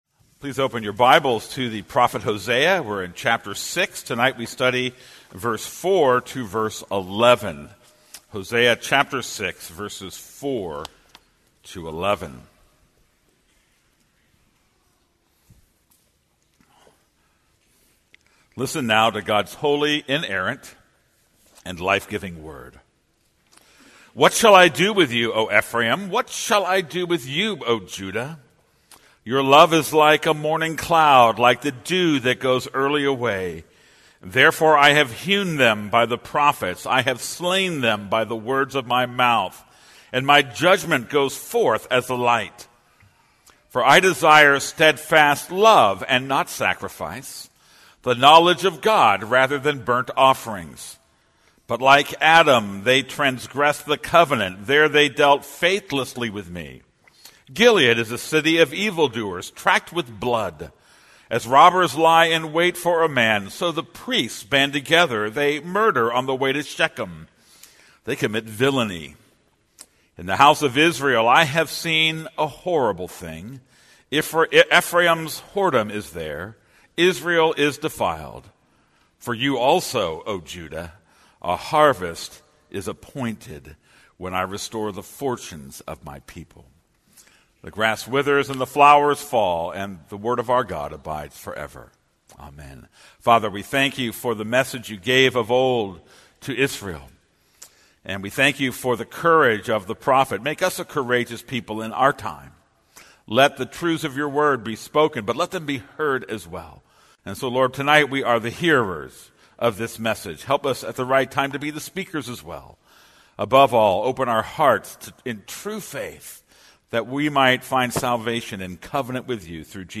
This is a sermon on Hosea 6:4-11.